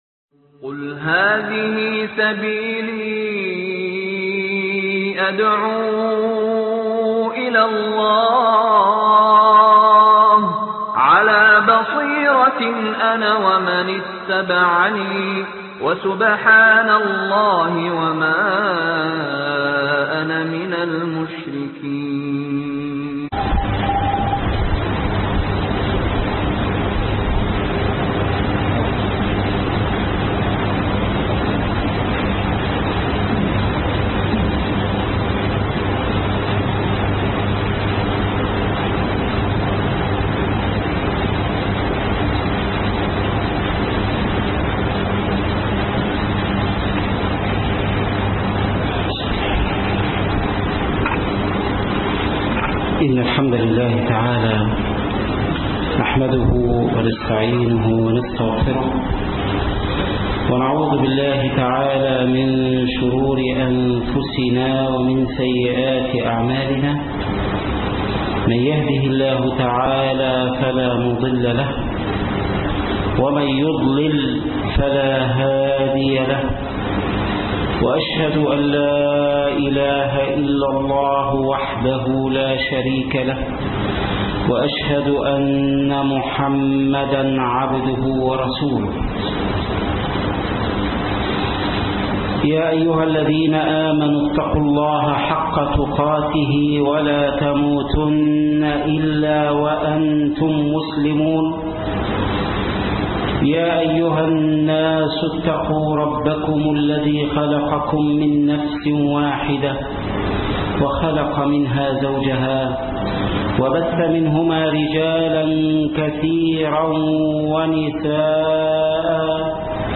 قصور الجنة _ خطب الجمعة